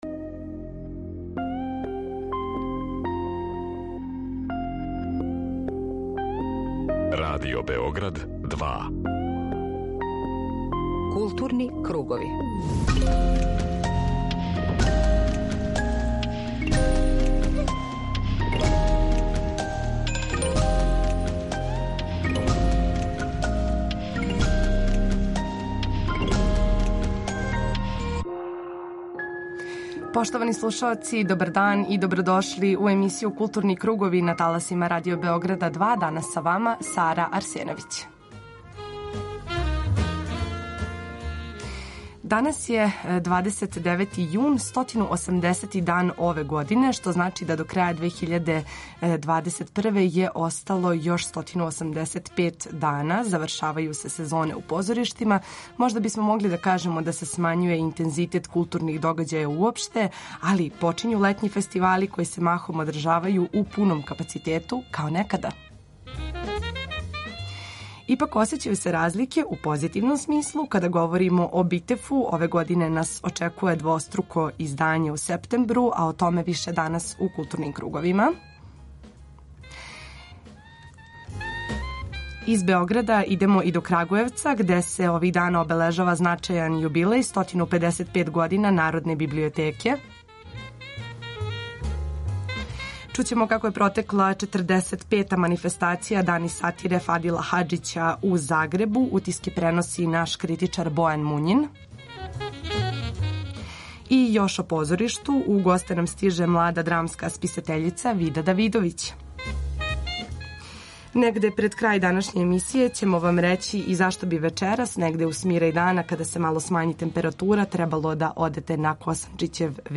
Централна културно-уметничка емисија Радио Београда 2.